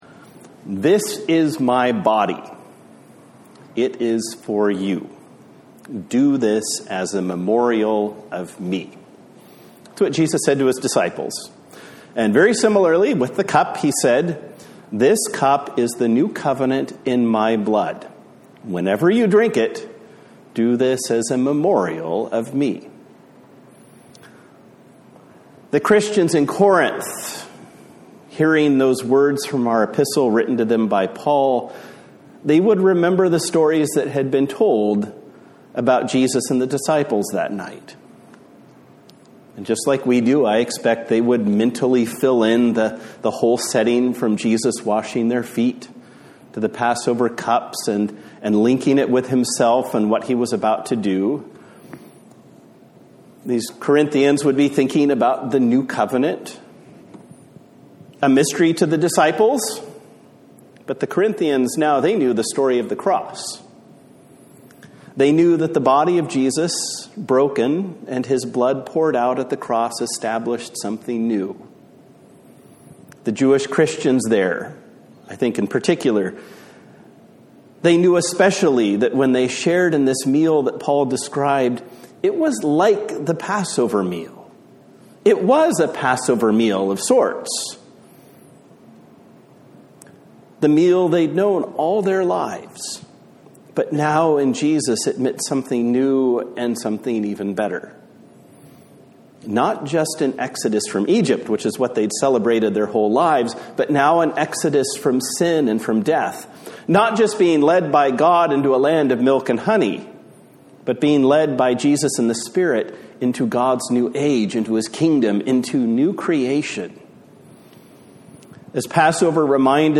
A Sermon for Maundy Thursday